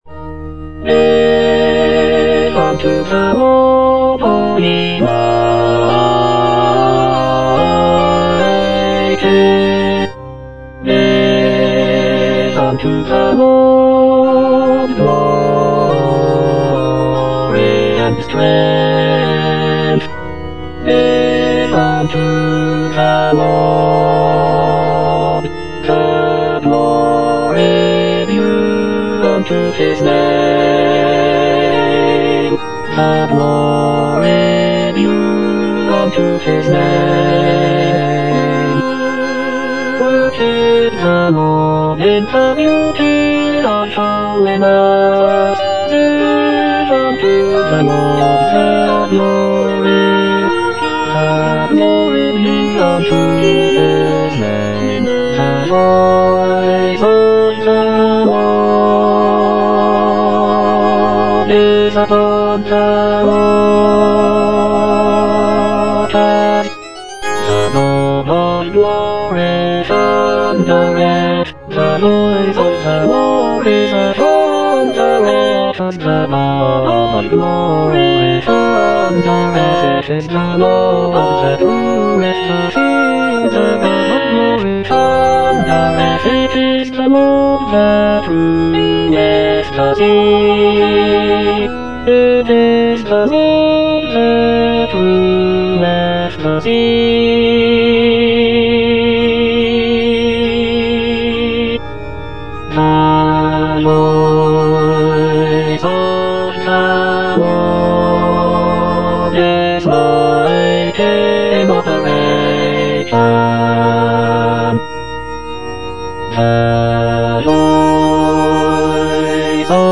E. ELGAR - GIVE UNTO THE LORD Bass I (Emphasised voice and other voices) Ads stop: auto-stop
"Give unto the Lord" is a sacred choral work composed by Edward Elgar in 1914.
The work is set for double chorus and orchestra, and features a majestic and triumphant sound that is characteristic of Elgar's music.